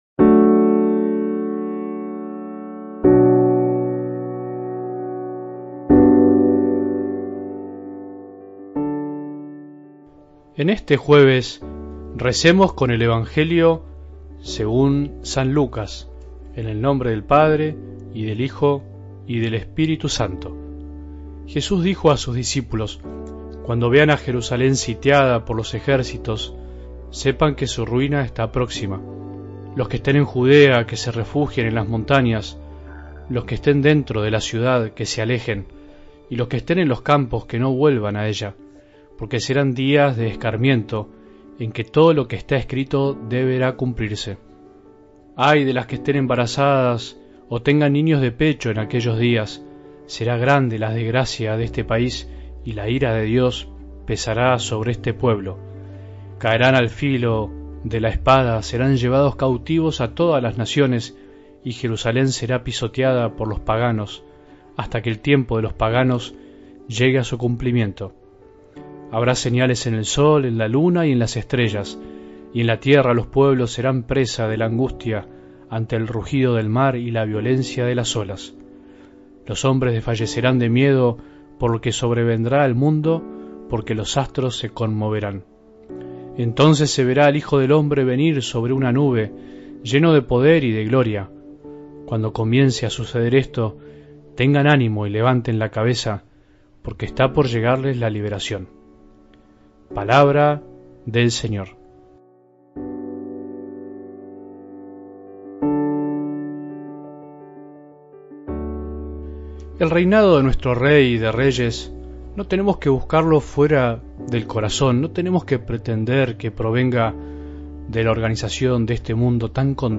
Oración del 28 de Noviembre de 2024
Reflexión Grupo Renovación del Paraguay